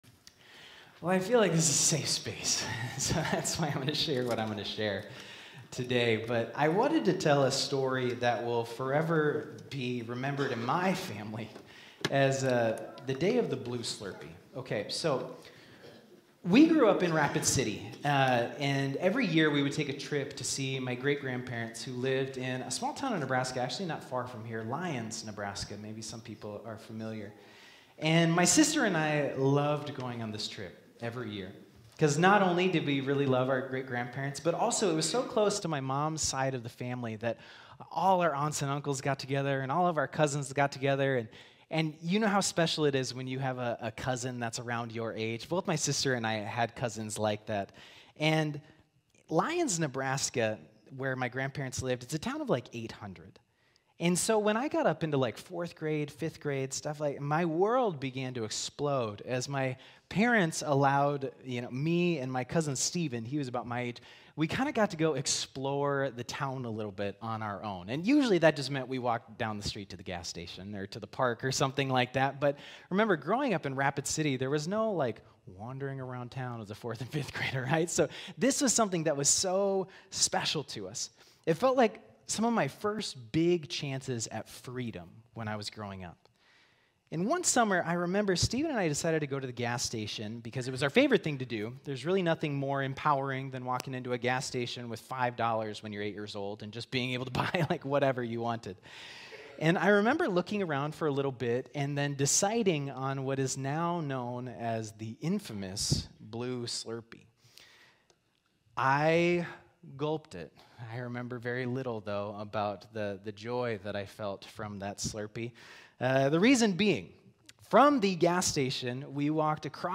Sermons | Harrisburg United Methodist Church
Thank you for joining us for Sunday Worship.